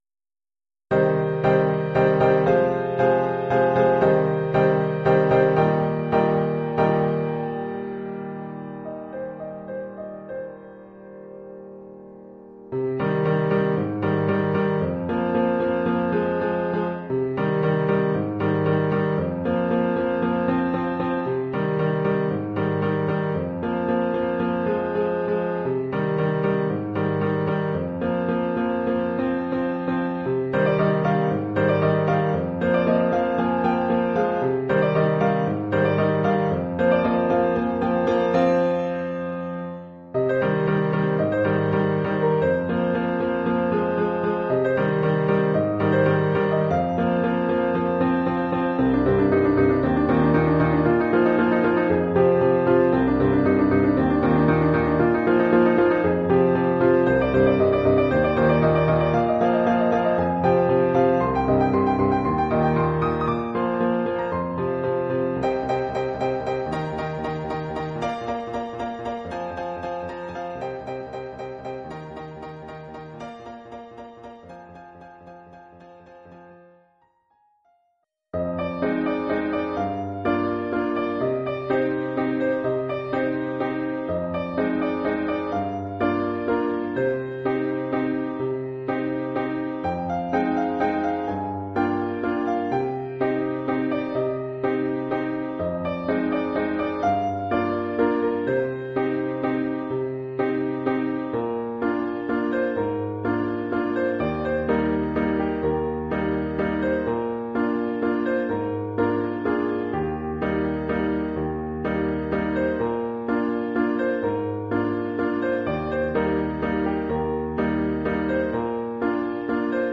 Collection : Piano
Oeuvre en 3 mouvements
pour piano solo.
Sous-titre : "Suite pianistique
en 3 paysages sonores".